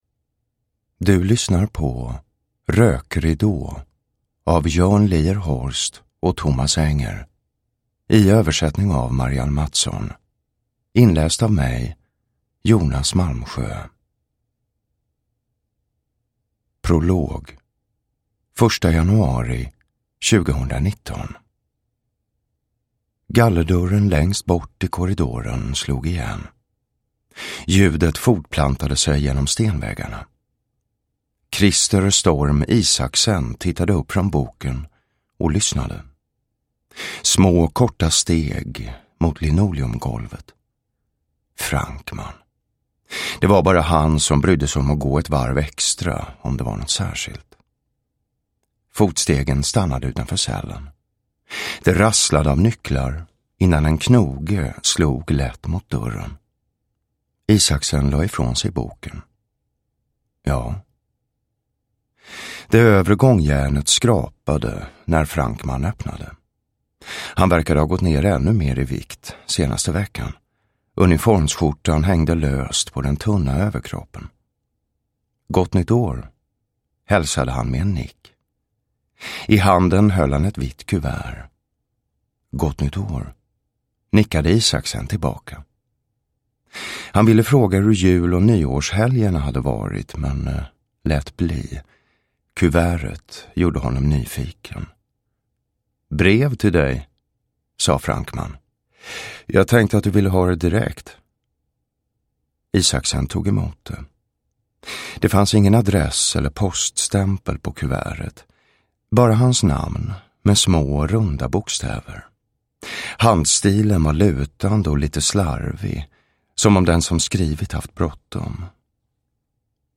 Rökridå – Ljudbok – Laddas ner
Uppläsare: Jonas Malmsjö